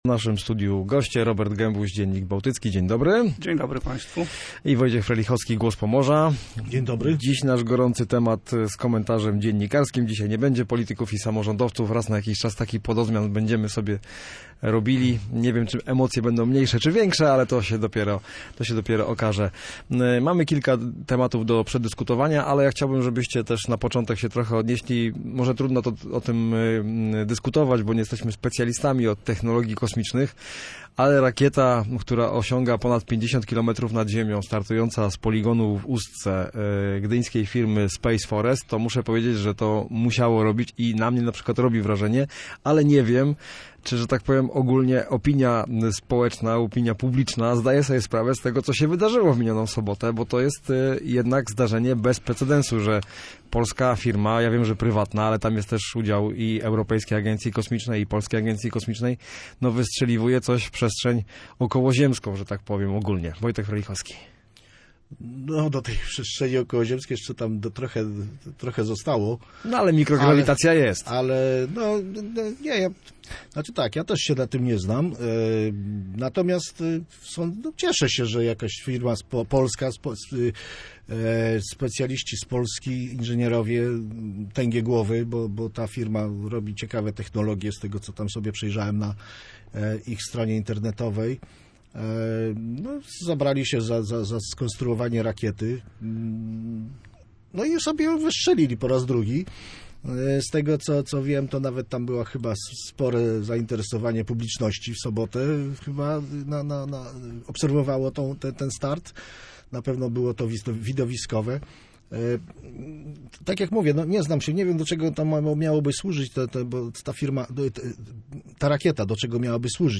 Lot rakiety Perun oraz militarne inwestycje norweskiej firmy na Pomorzu to tematy, o których rozmawiali dziennikarze w Studiu Słupsk Radia